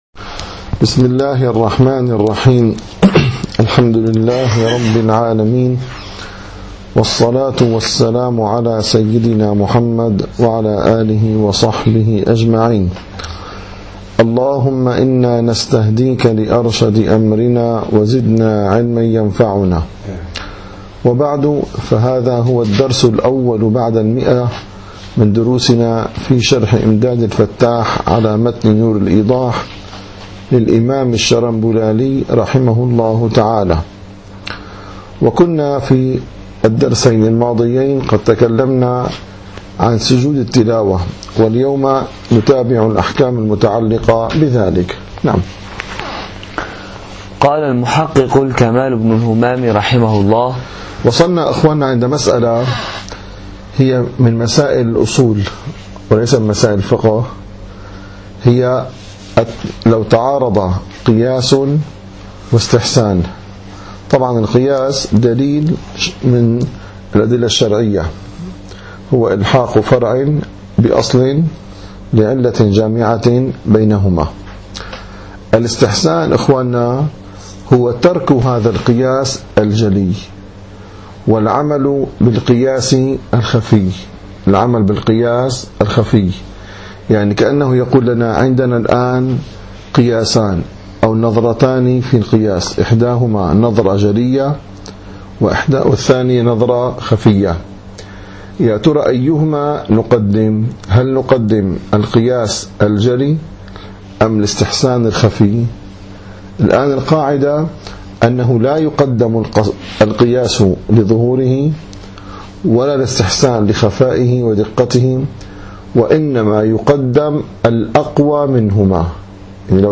- الدروس العلمية - الفقه الحنفي - إمداد الفتاح شرح نور الإيضاح - 101- قوله فإن قلت قد قالو إن تأديتها...